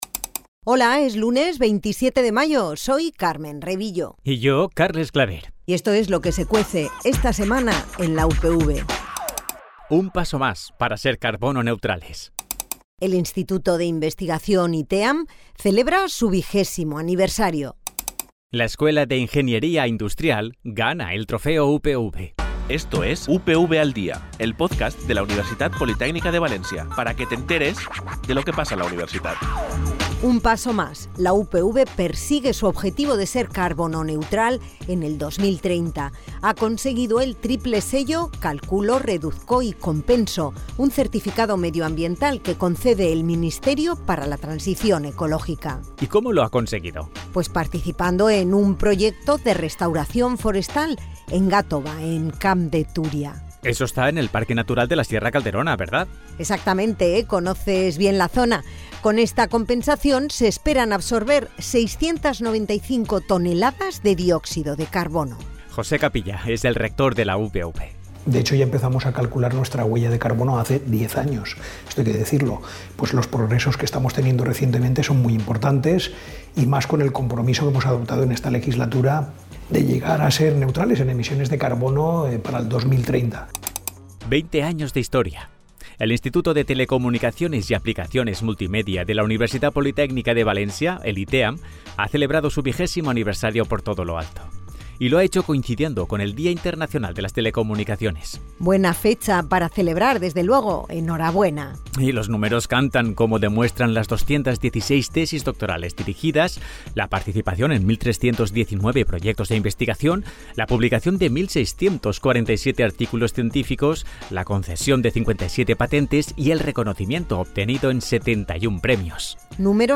Informativos
«UPV al día» es la versión sonora del Boletín Informativo para informarte de lo que pasa en la Universitat Politècnica de València.